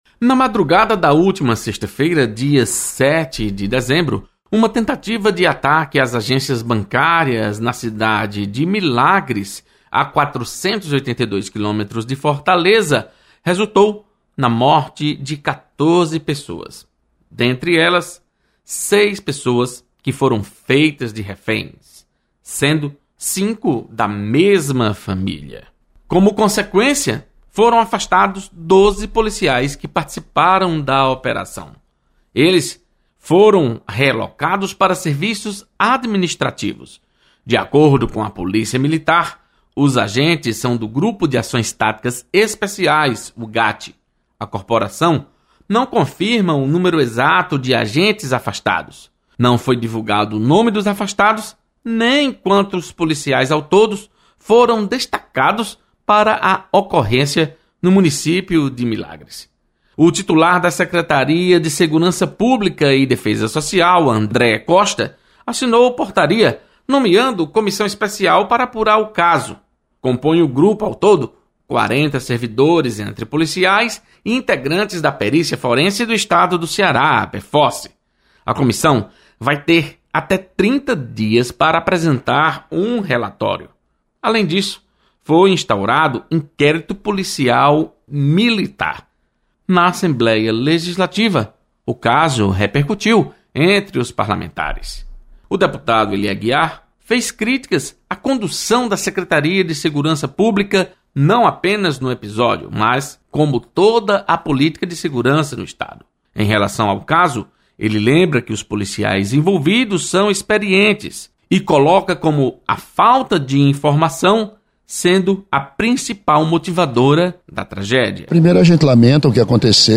Deputados comentam ação policial que resultou na morte de reféns em Milagres.